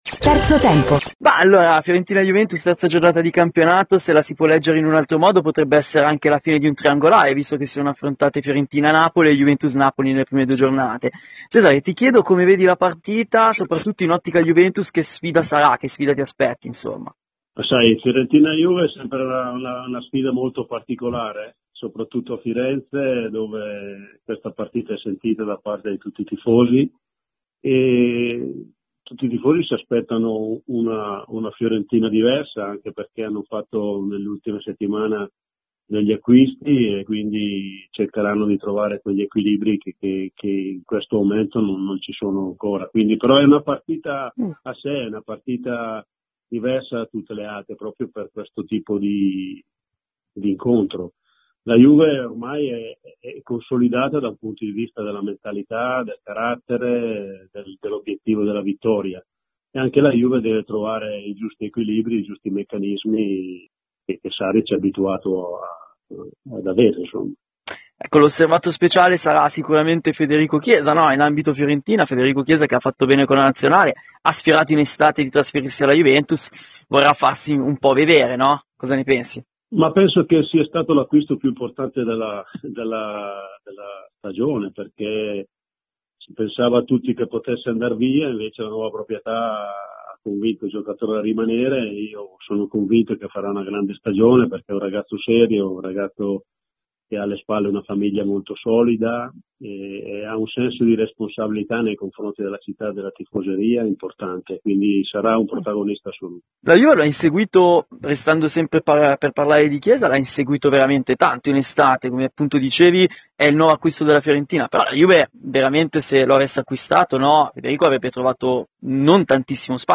L’ex ct dell’Italia Cesare Prandelli ha parlato ai microfoni di Radio Bianconera, nel corso di ‘Terzo Tempo’,: